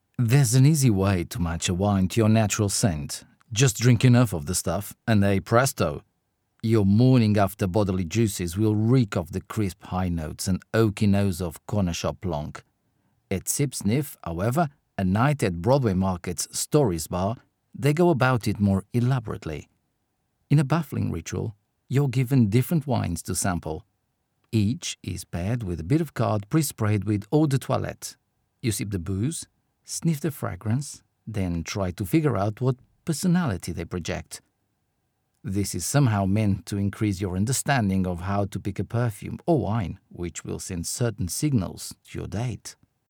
Portuguese, Male, Home Studio, 20s-30s